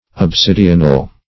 Search Result for " obsidional" : The Collaborative International Dictionary of English v.0.48: Obsidional \Ob*sid"i*o*nal\, a. [L. obsidionalis, from obsidio a siege, obsidere to besiege: cf. F. obsidional.